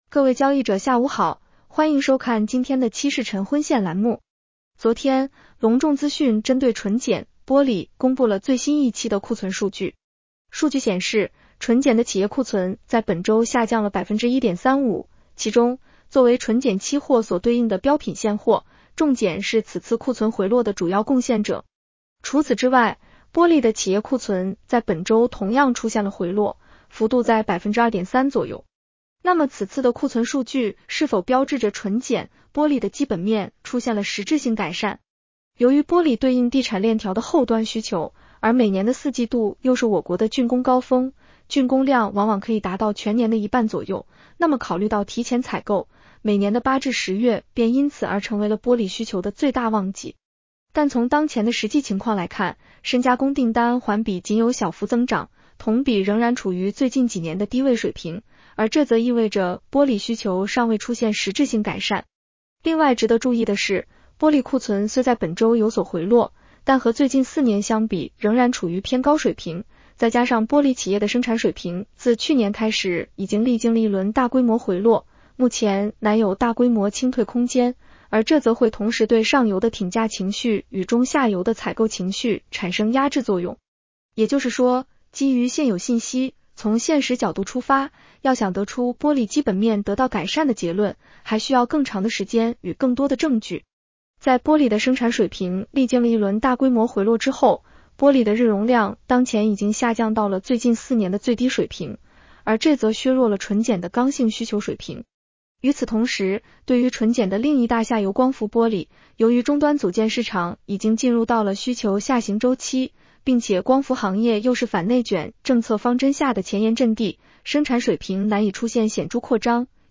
女声普通话版 下载mp3 各位交易者 下午 好，欢迎收看今天的《期市晨昏线》栏目。